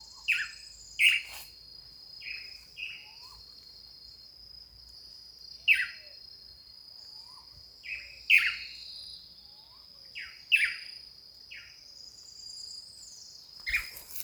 Tangará (Chiroxiphia caudata)
Nome em Inglês: Blue Manakin
Localidade ou área protegida: Reserva Privada San Sebastián de la Selva
Condição: Selvagem
Certeza: Fotografado, Gravado Vocal